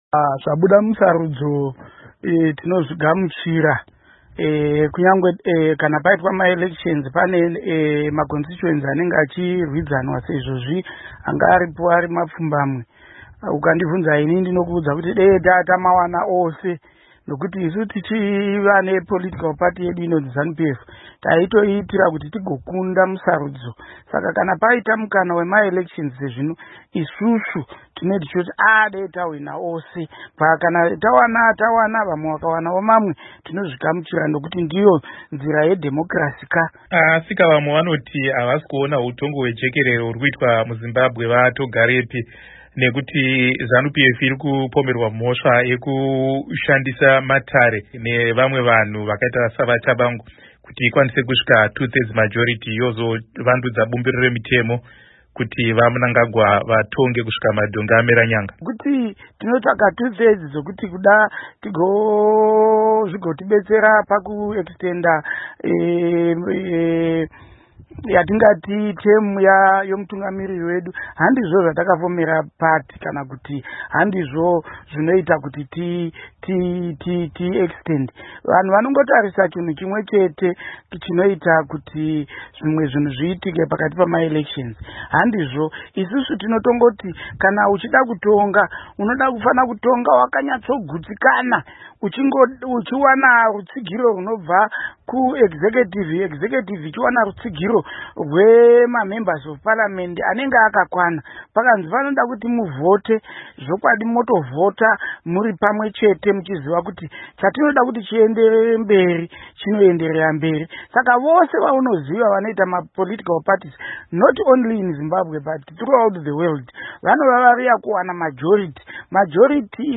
Hurukuro naVaPupurai Togarepi